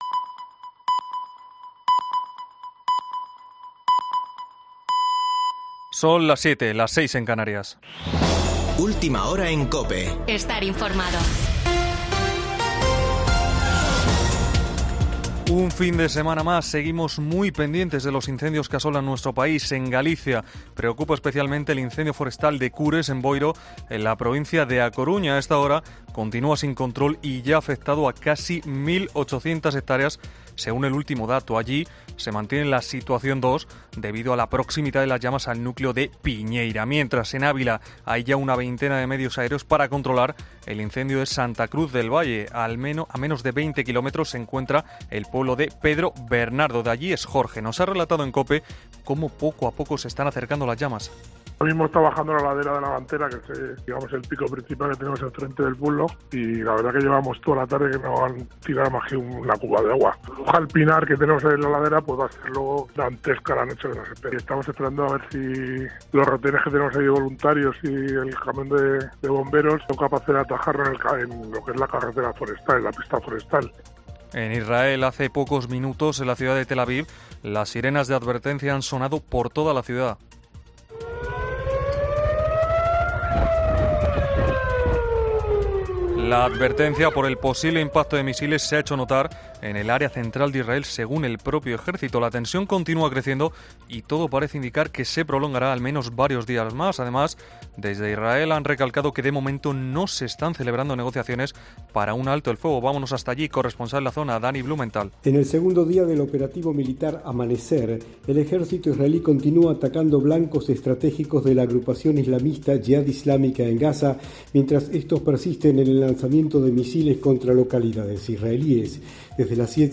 AUDIO: Boletín de noticias de COPE del 6 de agosto de 2022 a las 19.00 horas